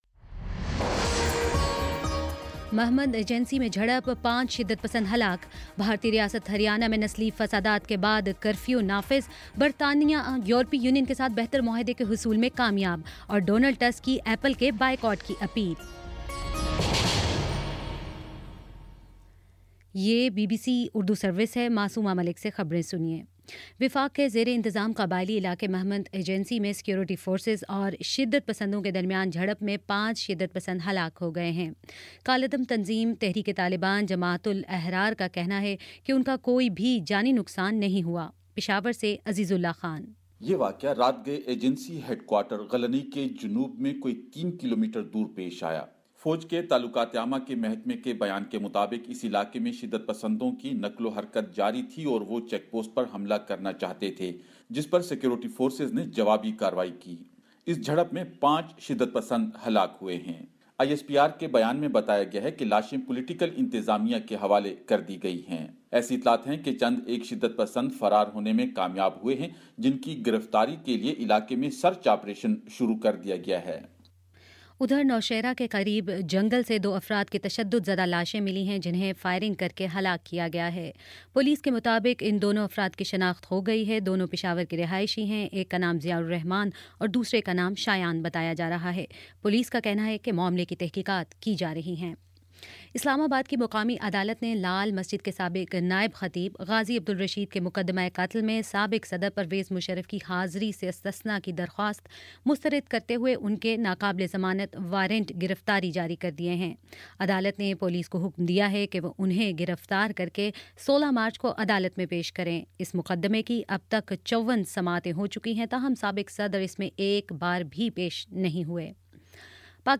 فروری 20 : شام پانچ بجے کا نیوز بُلیٹن